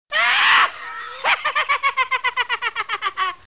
Nevetés csengőhang
Nevetés mp3 csengőhang